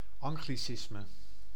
Ääntäminen
Ääntäminen France: IPA: [ɑ̃.ɡli.sism] Haettu sana löytyi näillä lähdekielillä: ranska Käännös Ääninäyte Substantiivit 1. anglicisme {n} Muut/tuntemattomat 2.